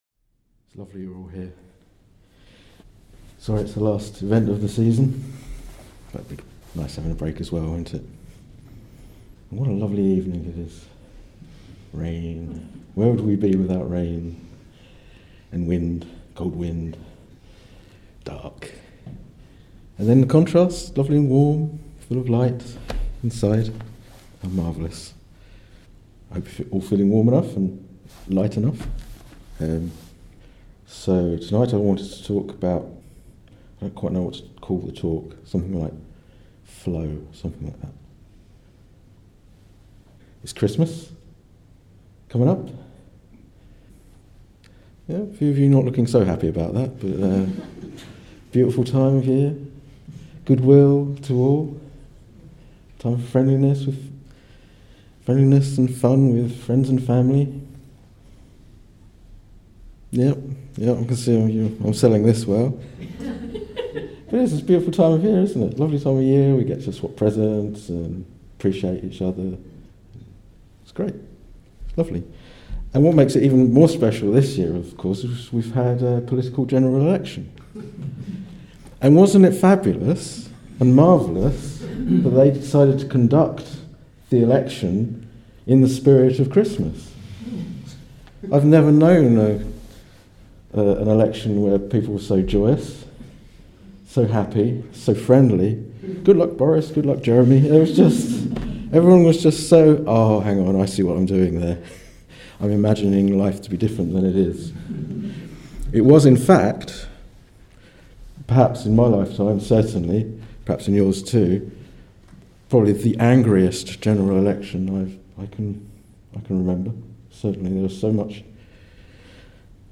This talk was given in December 2019